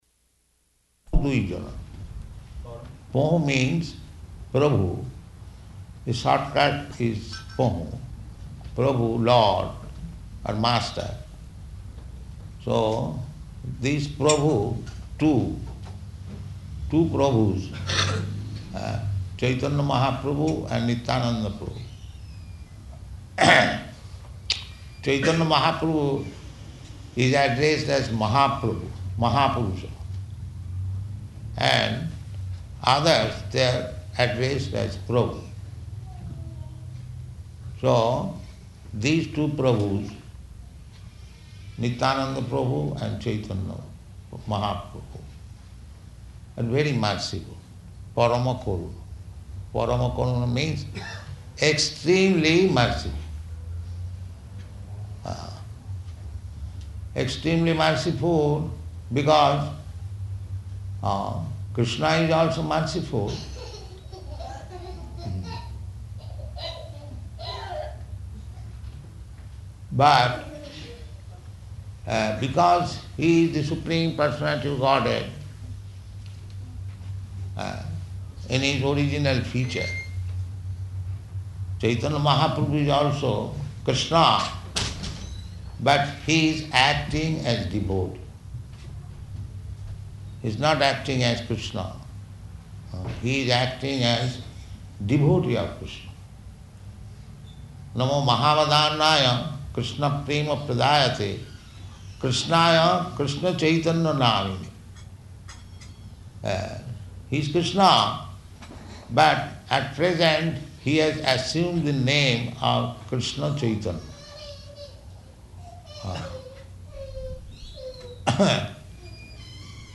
Purport to Parama Koruṇa --:-- --:-- Type: Purport Dated: February 28th 1975 Location: Atlanta Audio file: 750228PU.ATL.mp3 Prabhupāda: ...pahū dui jana.